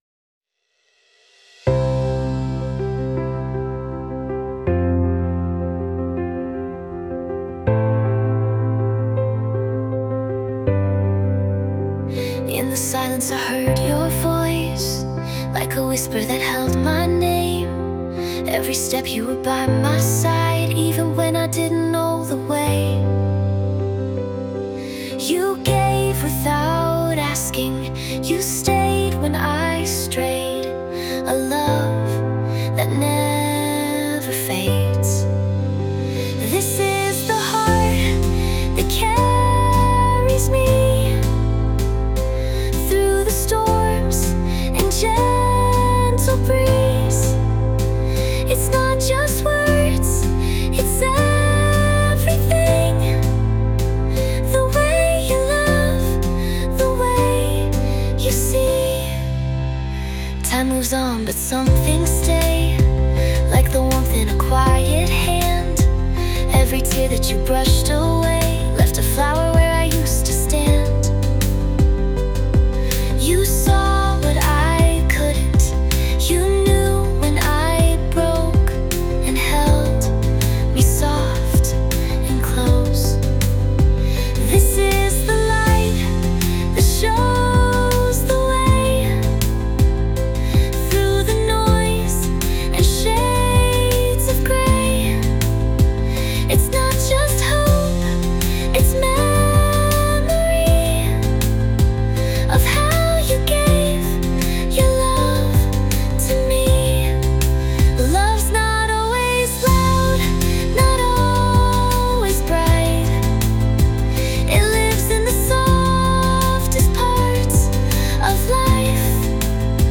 著作権フリーオリジナルBGMです。
女性ボーカル（洋楽・英語）曲です。
静かに寄り添うように、
さまざまな形の愛と絆を描いた温かなバラードです。